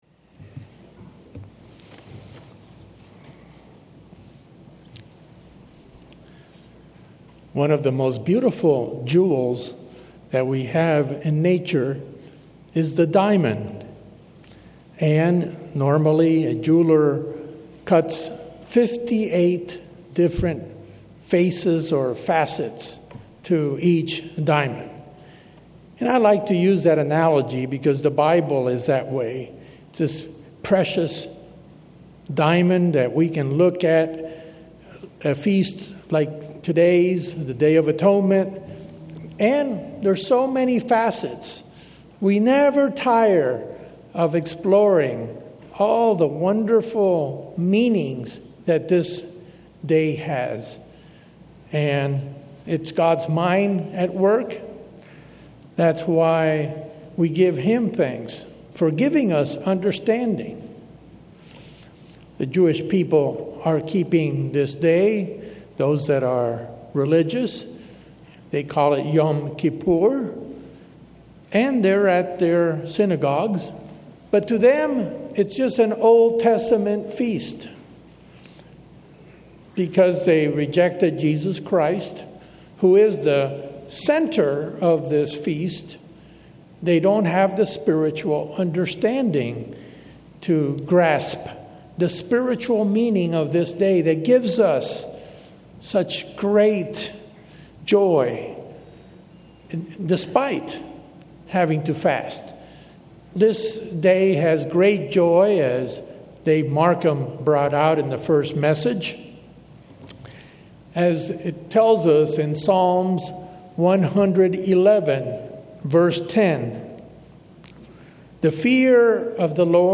In this sermon we review what the two goats of the Atonement symbolize for the new testament Christian.